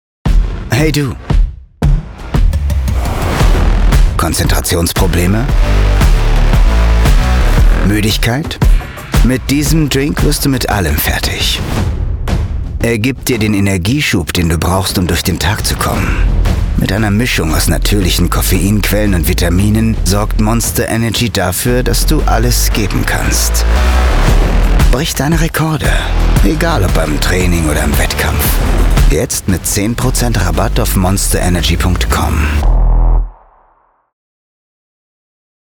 markant, dunkel, sonor, souverän
Mittel minus (25-45)
Commercial (Werbung)